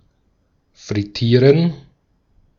Ääntäminen
IPA: /fʁɪˈtiːʁən/ IPA: [fʁɪˈtʰiːɐ̯n]